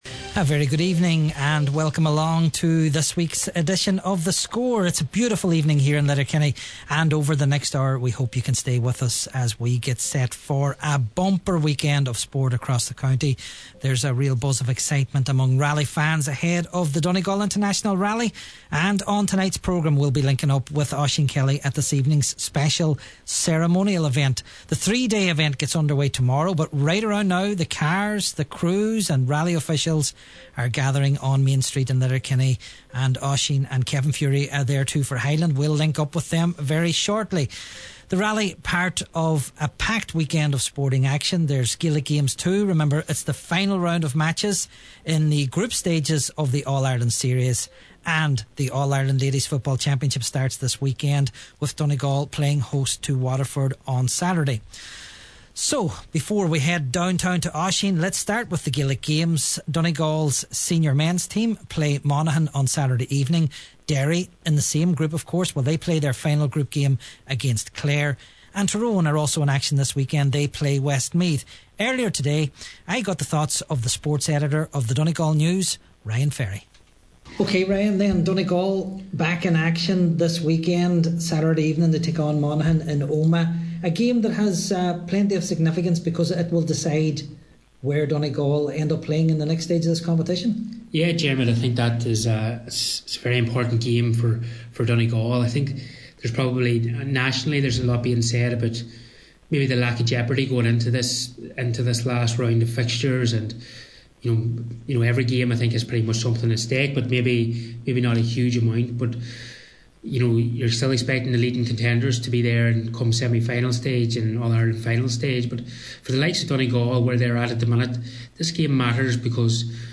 The Score LIVE from the Donegal Rally Ceremonial start